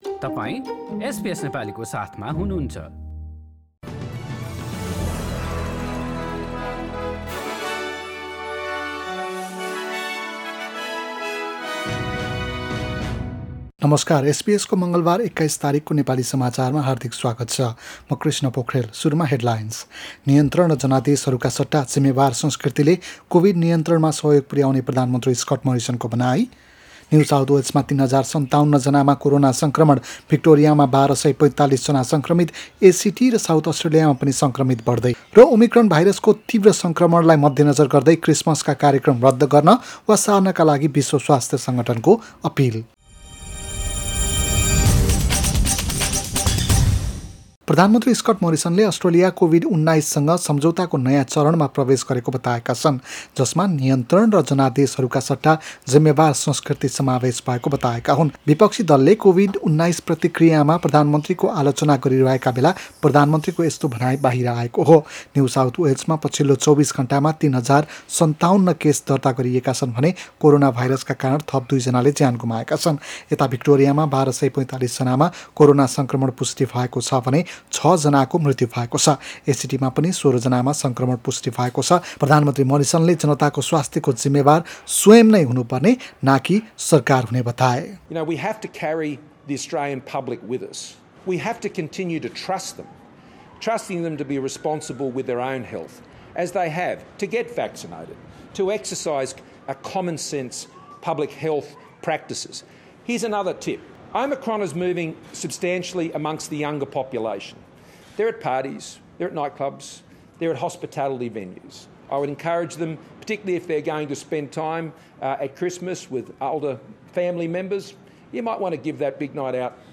एसबीएस नेपाली अस्ट्रेलिया समाचार: मंगलबार २१ डिसेम्बर २०२१